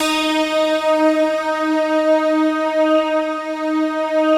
Index of /90_sSampleCDs/Optical Media International - Sonic Images Library/SI1_Rich&Mellow/SI1_Mellow Pad
SI1 PLUCK06L.wav